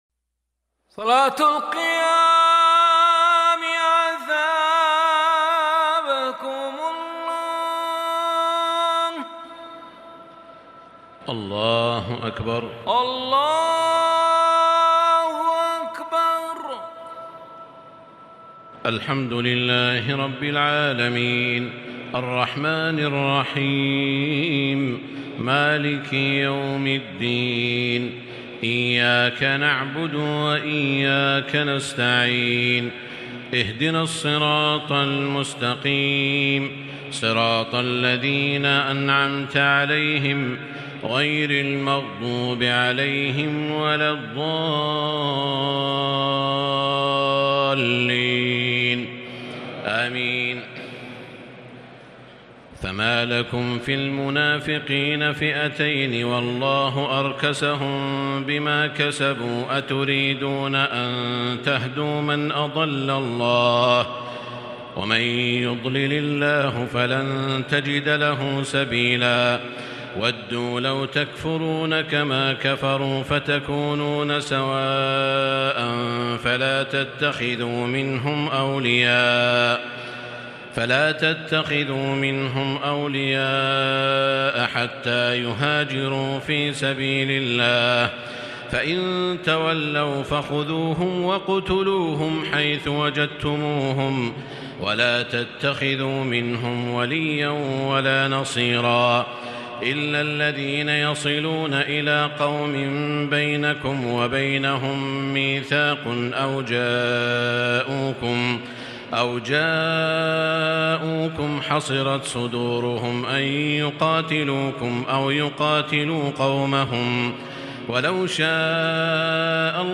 تراويح الليلة الخامسة رمضان 1440هـ من سورة النساء (88-154) Taraweeh 5 st night Ramadan 1440H from Surah An-Nisaa > تراويح الحرم المكي عام 1440 🕋 > التراويح - تلاوات الحرمين